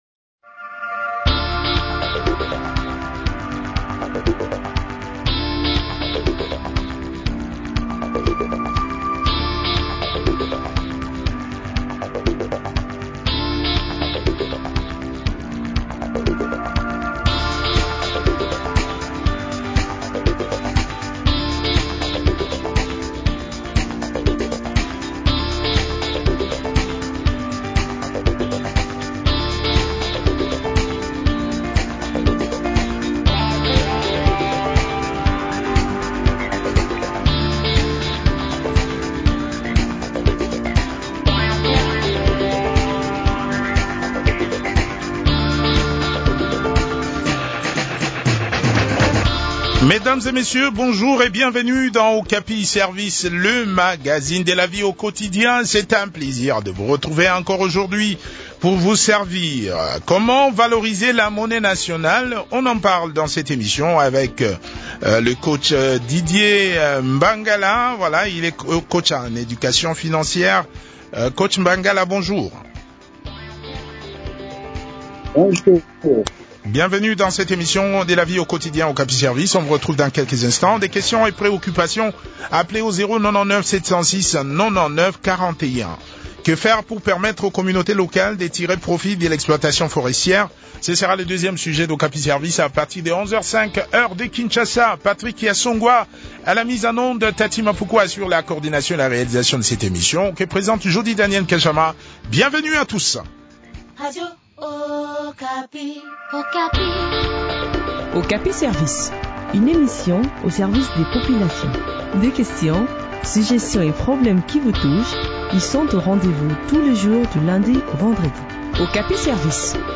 coach en éducation financière.